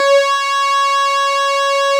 snes_synth_061.wav